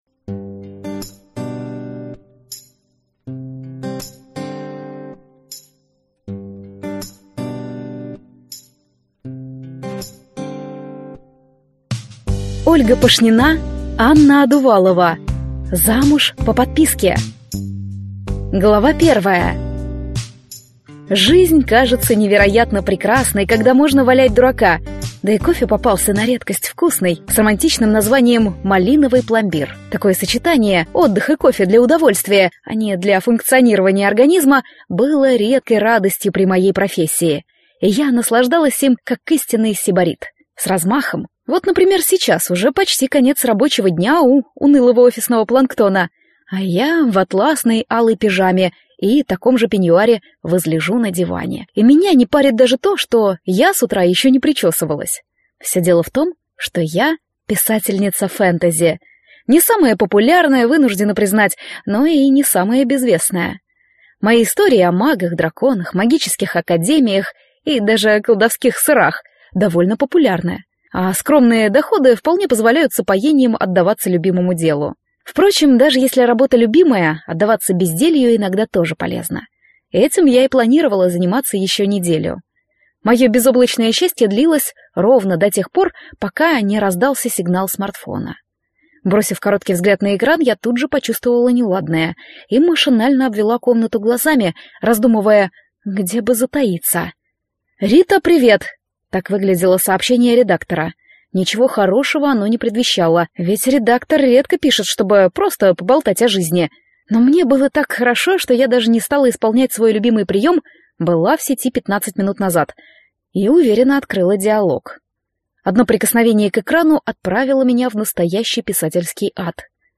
Аудиокнига Замуж по подписке | Библиотека аудиокниг